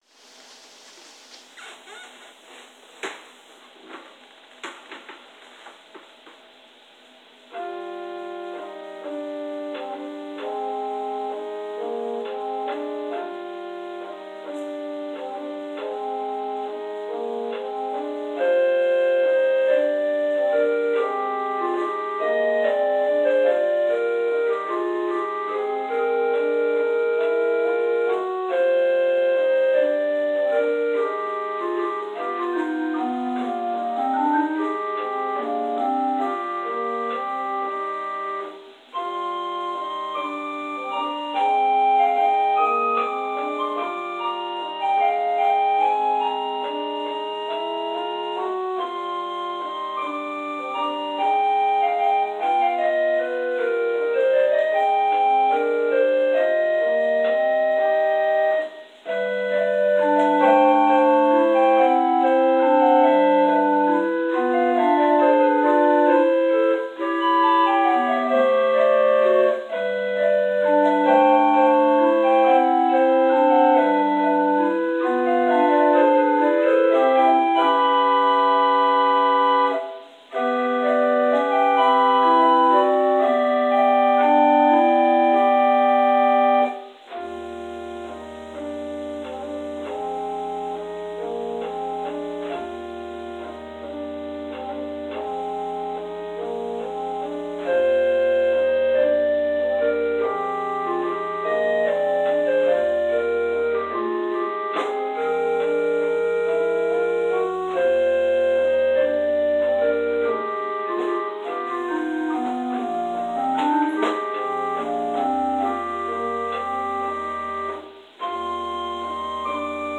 Interlude: “A Gentle Song” – David Lasky